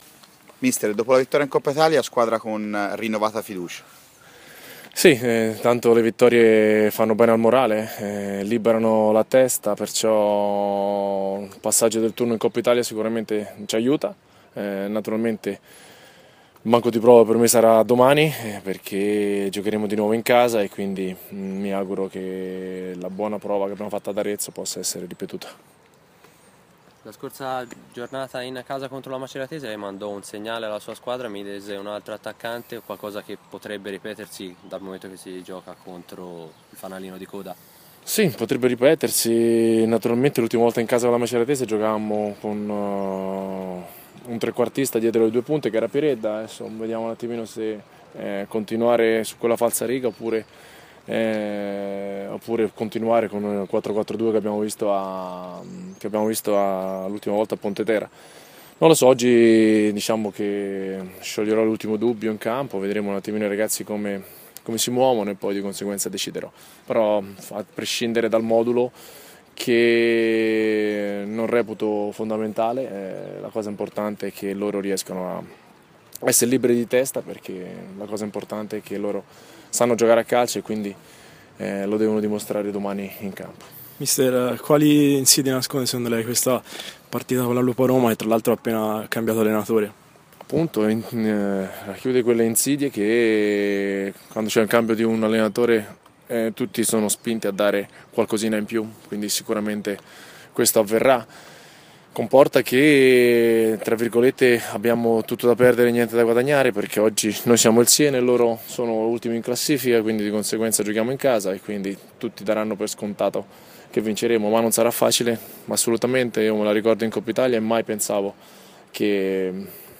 L’audio completo delle dichiarazioni di Atzori alla vigilia di Robur Siena-Lupa Roma: